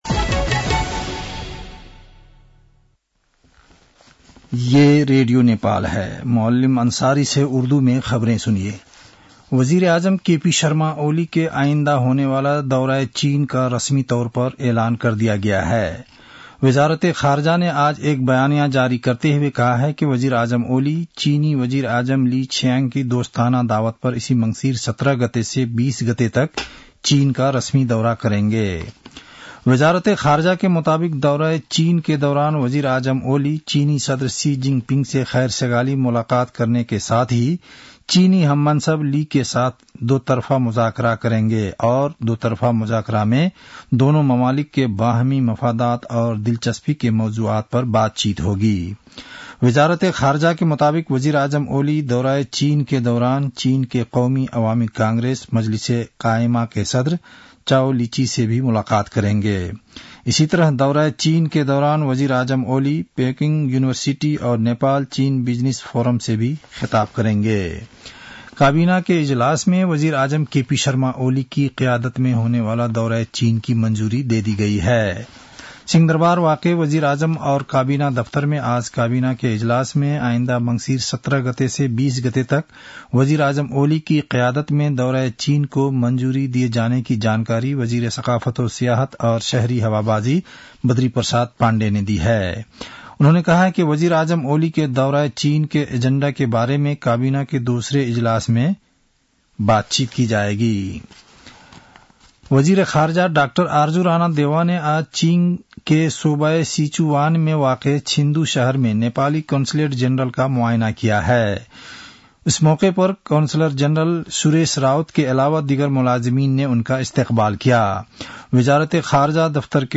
उर्दु भाषामा समाचार : १५ मंसिर , २०८१
Urdu-news-8-14.mp3